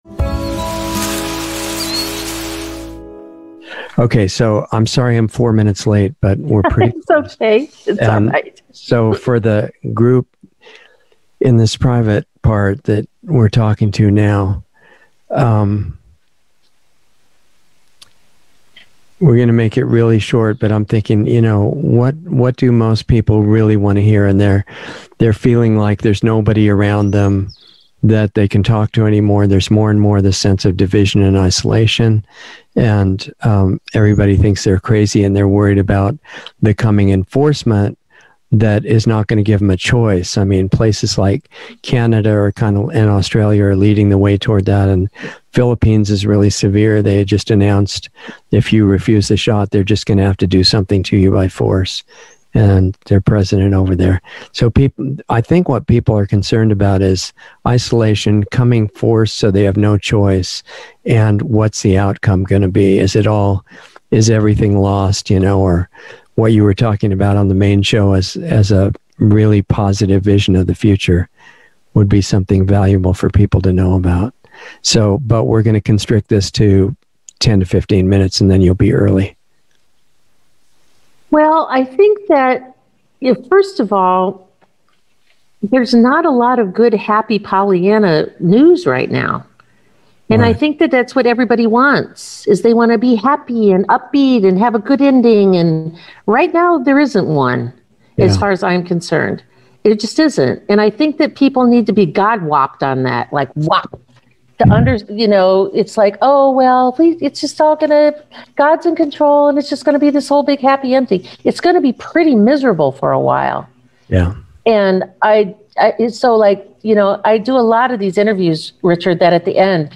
Planetary Healing Club - Dr. Sherri Tenpenny - Insider Interview 6/24/21